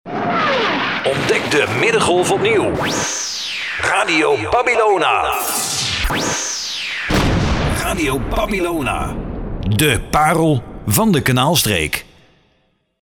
Onze Jingles / Unser Jingles